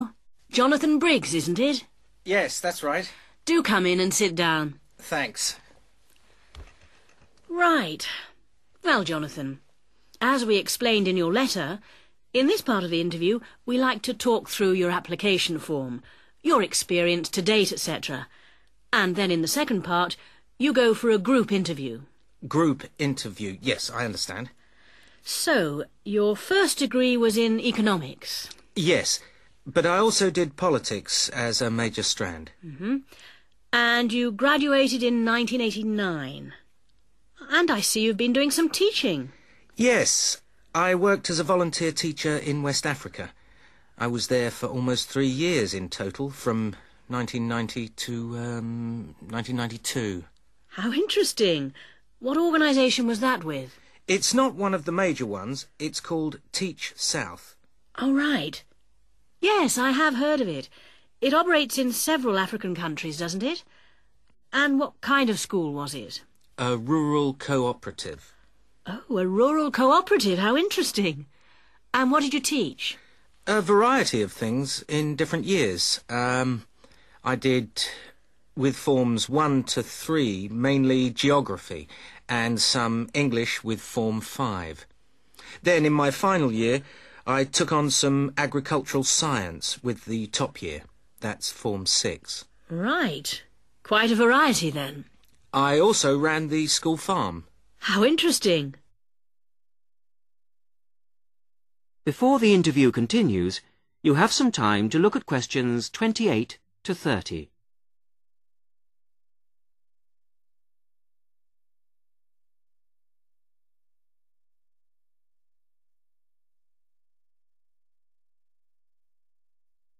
Trong phần 3 của bài thi IELTS Listening, thí sinh thường gặp các đoạn hội thoại học thuật giữa hai hoặc nhiều người – chẳng hạn như sinh viên và giảng viên đang thảo luận về một dự án, bài nghiên cứu, hay đơn đăng ký chương trình học.